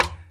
Game Perc.WAV